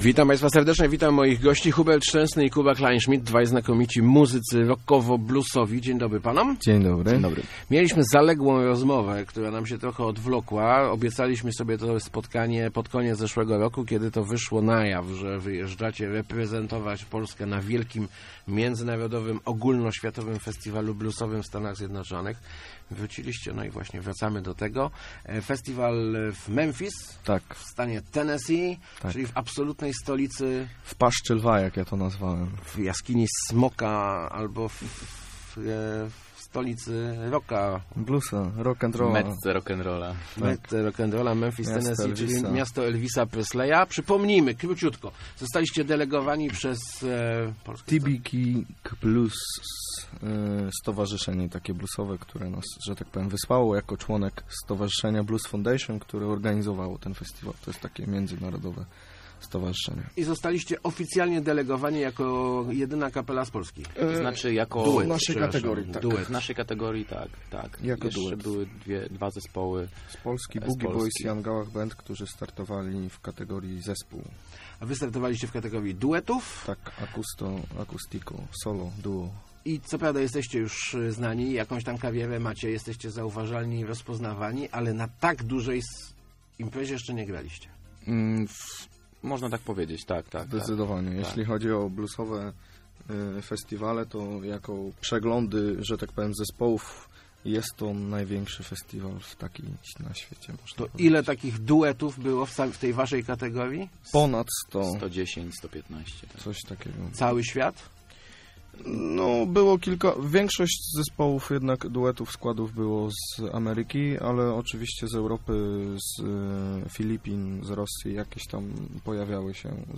Trema była, ale skończyła się po zagraniu pierwszego utworu - mówili w Rozmowach Elki muzycy